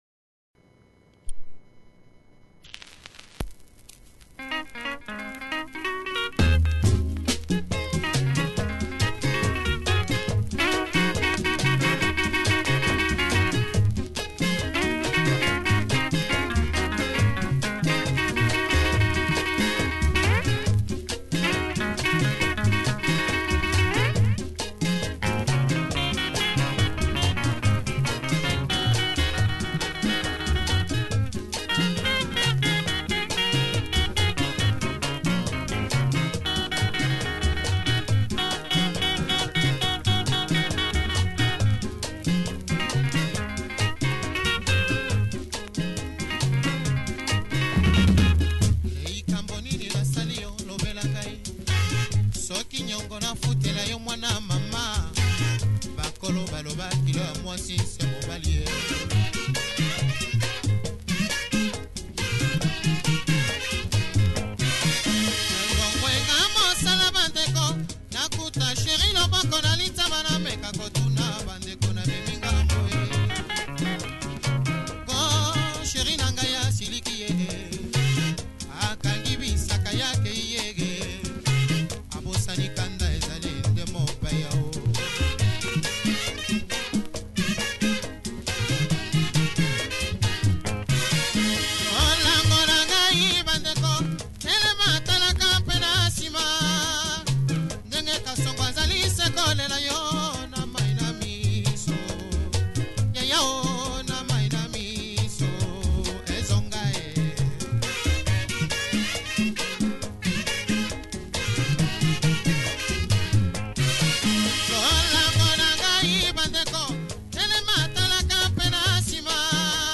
Killer Lingala track with great backing and vocals.